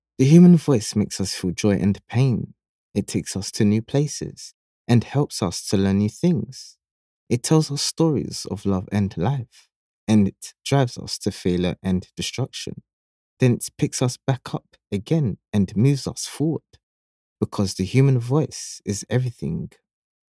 UK Voiceover Professionals
Authentic and native British speakers bring a professional, rich, refined and smooth sound to your next voice project.
English (Caribbean)
Yng Adult (18-29) | Adult (30-50)